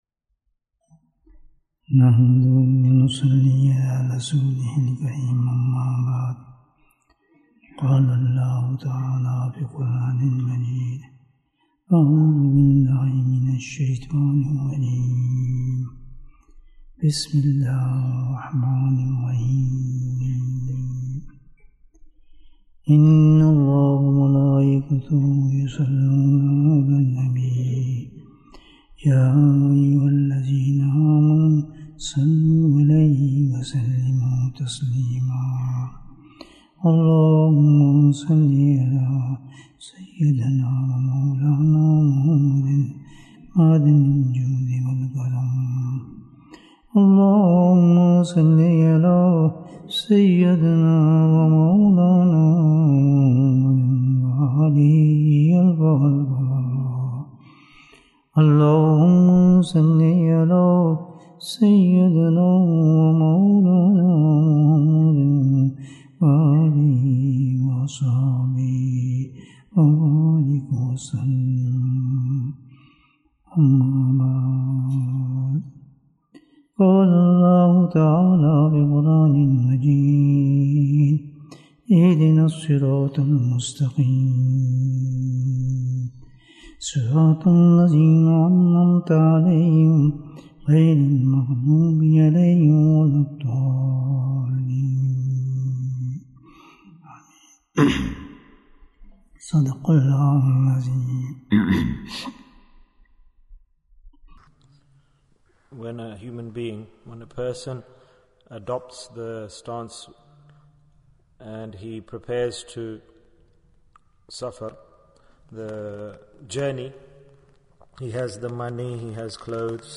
Do You Know What the Passport for Aakhirah is? Bayan, 56 minutes22nd September, 2022